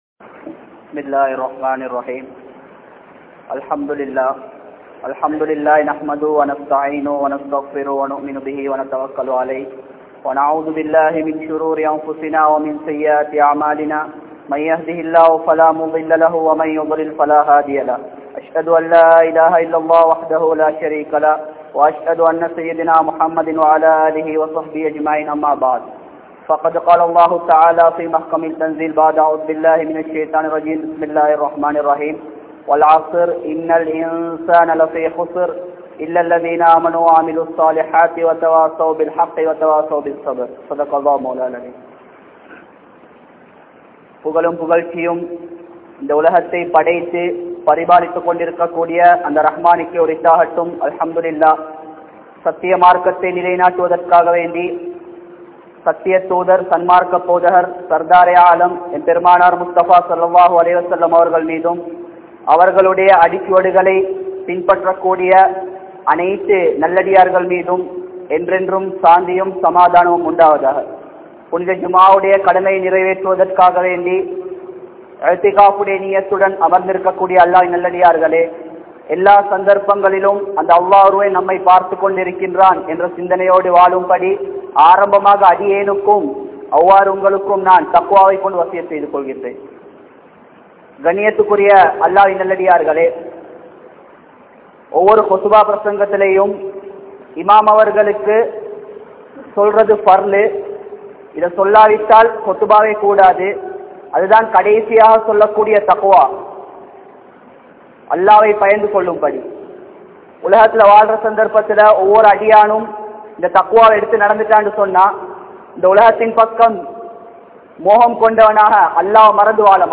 Pengalum Narahamum (பெண்களும் நரகமும்) | Audio Bayans | All Ceylon Muslim Youth Community | Addalaichenai